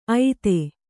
♪ aite